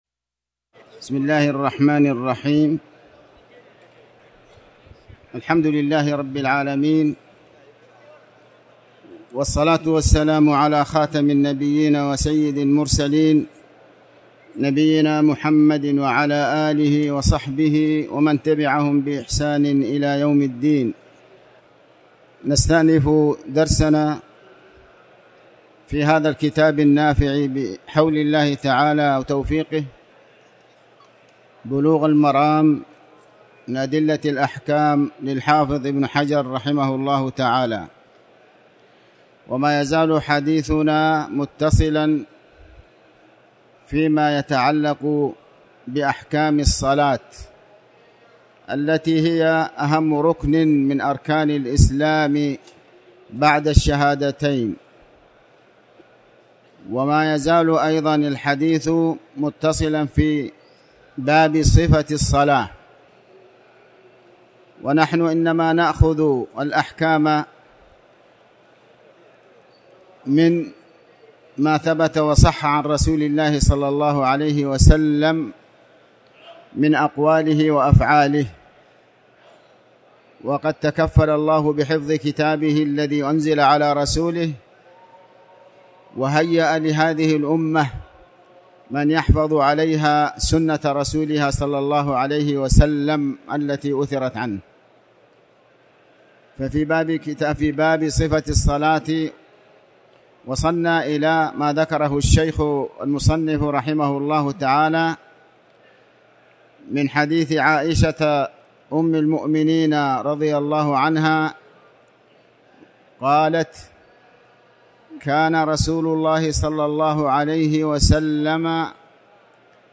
تاريخ النشر ٢٦ ذو الحجة ١٤٣٩ هـ المكان: المسجد الحرام الشيخ